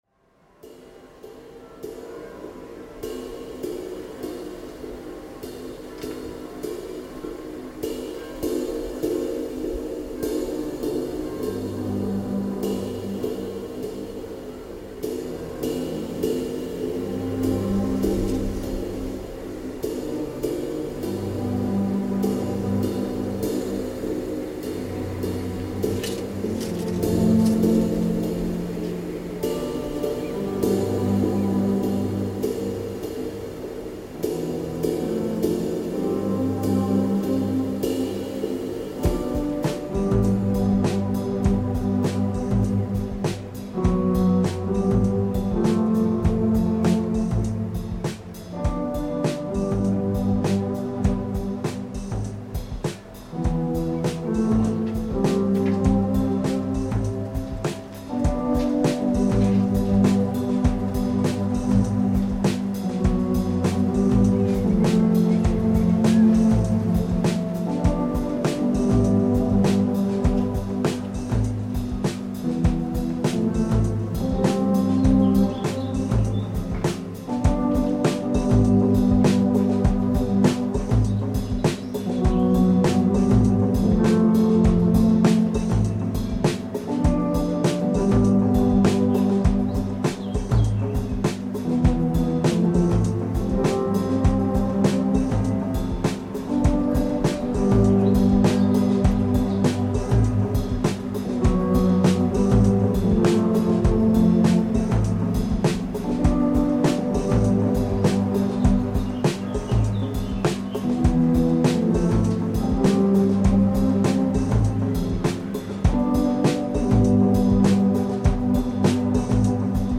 Norwegian harbour sounds reimagined